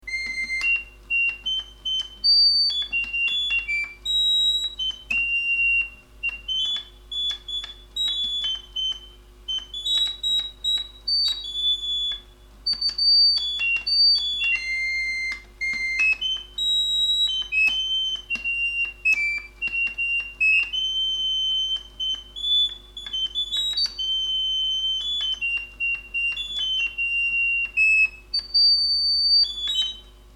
Serinette
Les aléas des divers ajustages m'on amenés à un peu trop raccourcir les tuyaux... l'instrument est donc quelques demi-tons plus haut qu'il ne faudrait.
On soulève le clavier, on cale le cylindre sur l'un des 8 airs, et c'est parti pour tourner la petite manivelle à environ 2 tours par seconde!
Marseillaise (Mp3 de 595 Ko) Le rythme est assez particulier, mais le pointage du cylindre est comme ça! Quelques tuyaux sont également un peu limite du point de vue accord...